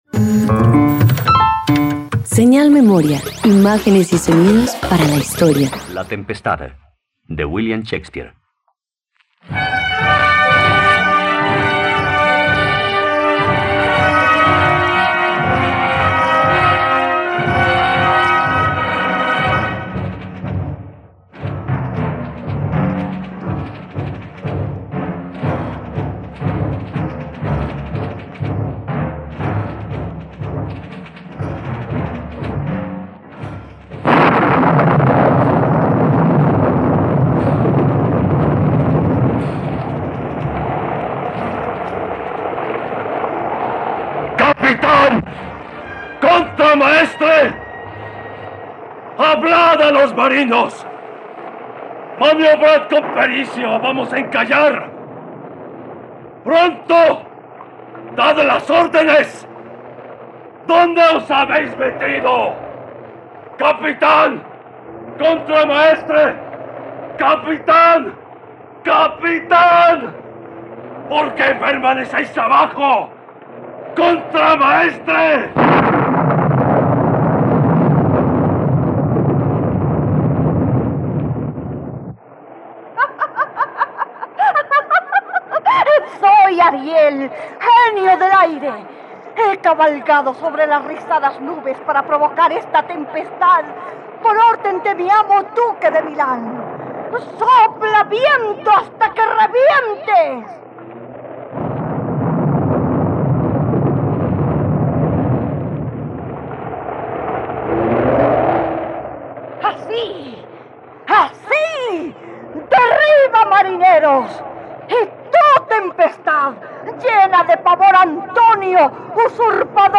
..Radioteatro. Escucha la adaptación radiofónica de “La tempestad” del dramaturgo inglés William Shakespeare, disponible en la plataforma de streaming RTVCPlay.